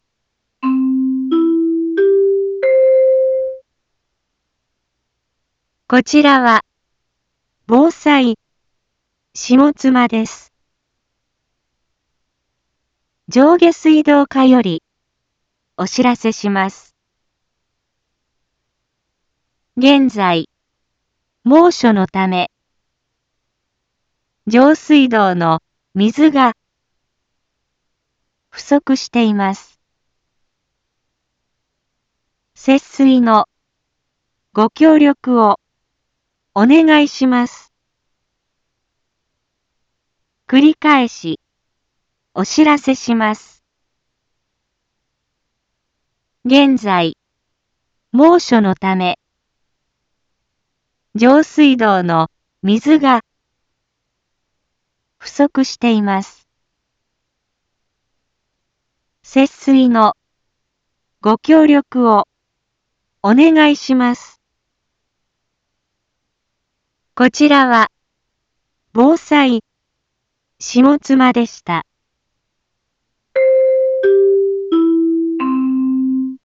一般放送情報
Back Home 一般放送情報 音声放送 再生 一般放送情報 登録日時：2022-06-27 10:01:20 タイトル：節水のお願いについて インフォメーション：こちらは、防災下妻です。